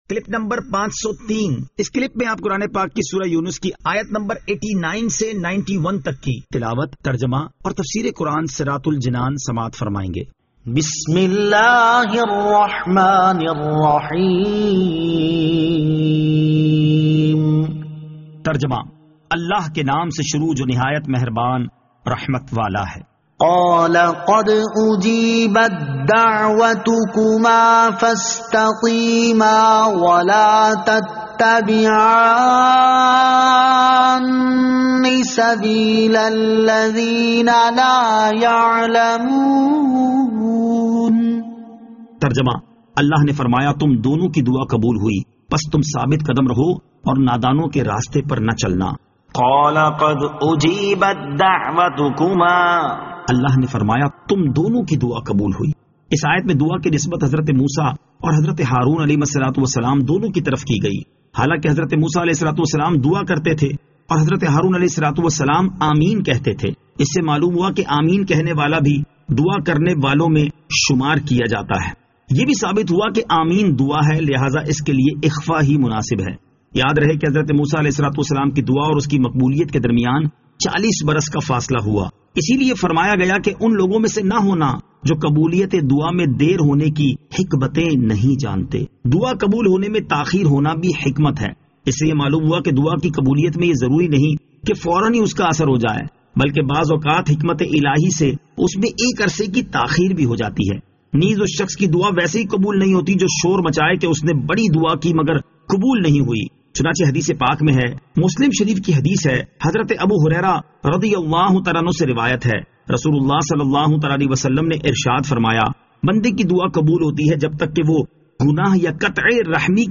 Surah Yunus Ayat 89 To 91 Tilawat , Tarjama , Tafseer